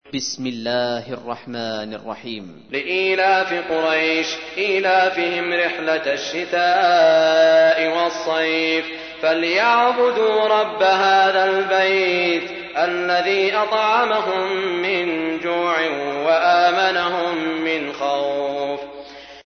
تحميل : 106. سورة قريش / القارئ سعود الشريم / القرآن الكريم / موقع يا حسين